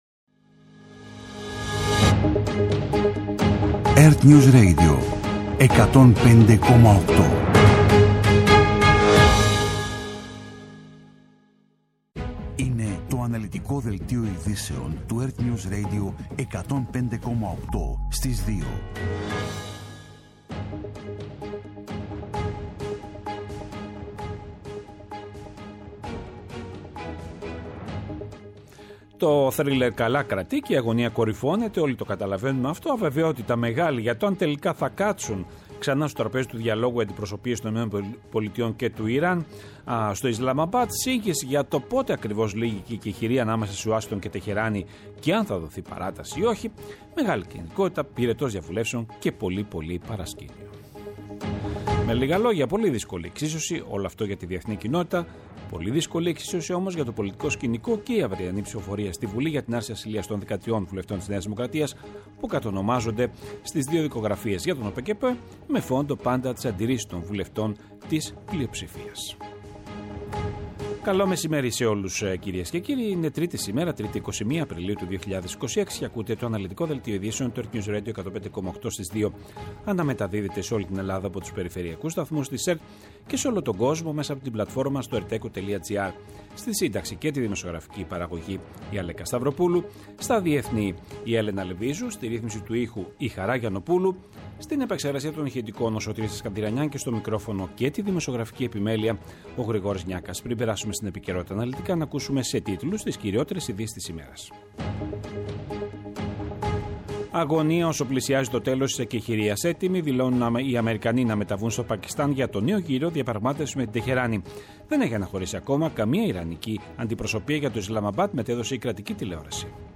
Το αναλυτικό ενημερωτικό μαγκαζίνο στις 14:00.
Με το μεγαλύτερο δίκτυο ανταποκριτών σε όλη τη χώρα, αναλυτικά ρεπορτάζ και συνεντεύξεις επικαιρότητας.